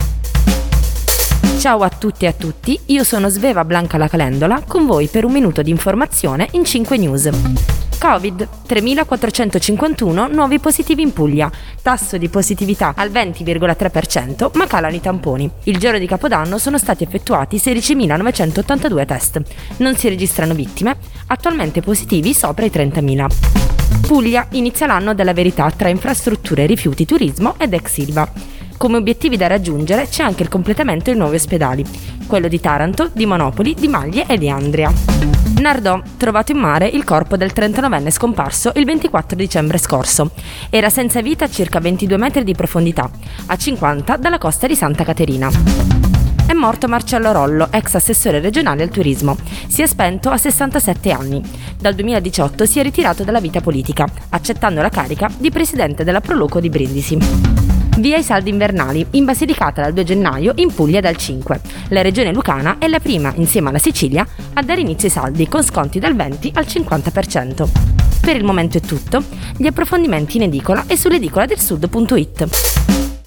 Giornale radio alle ore 7.